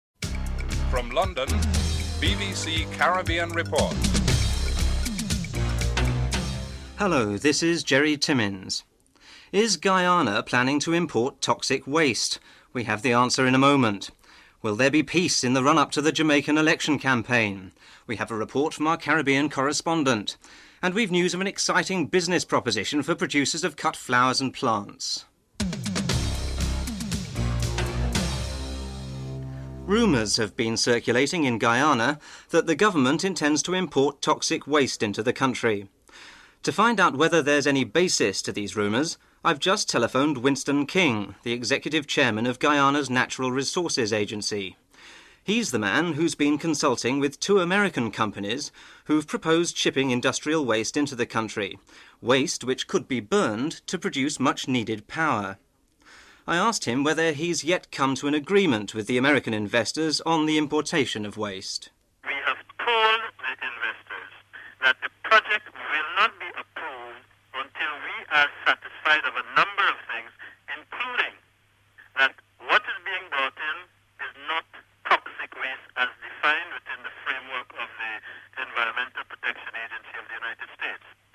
1. Headlines: Is Guyana planning to import toxic waste? ; Will there be peace in the run-up to the Jamaican election campaign? ; Exciting business proposition for producers of cut flowers. (00:06-00:24)